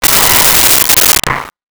Rocket Launcher Sci Fi 03
Rocket Launcher Sci Fi 03.wav